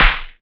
Index of /90_sSampleCDs/USB Soundscan vol.51 - House Side Of 2 Step [AKAI] 1CD/Partition C/07-CLAPS